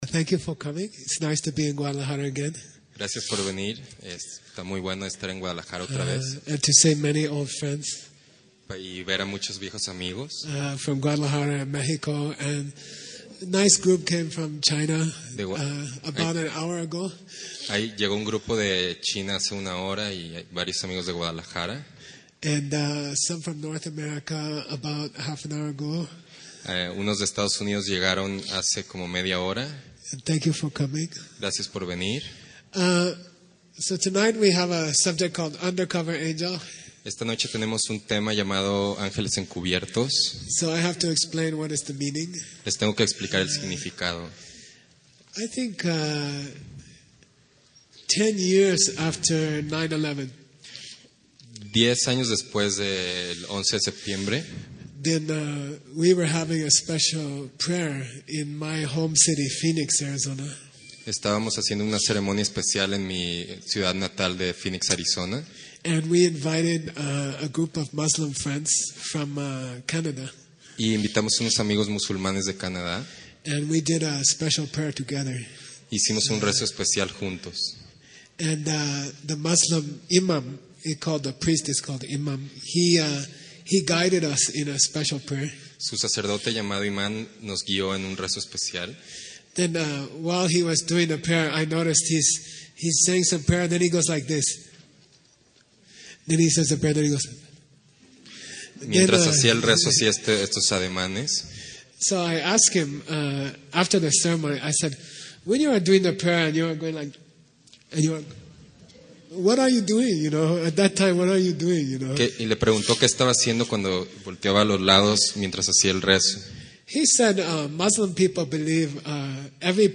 Taught in english, interpreted en español.